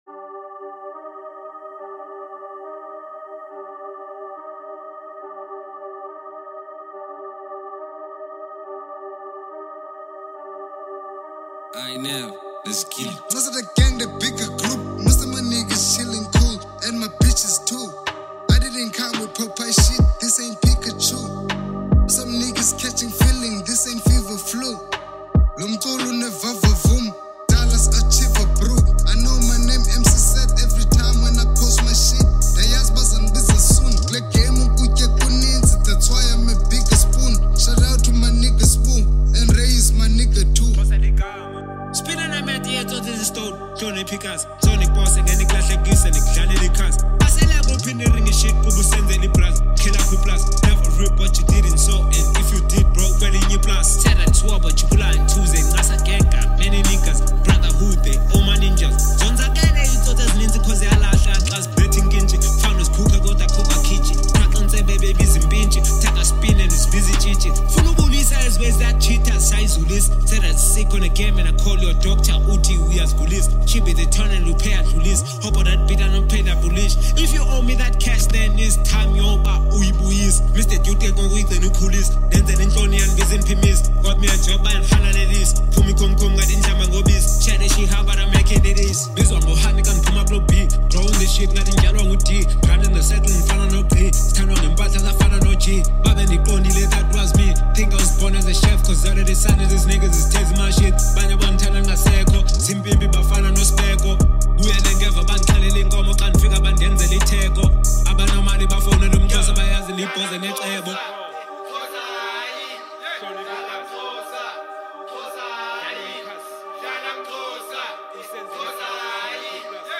combining strong vocals